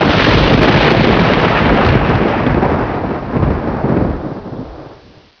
thundrk.wav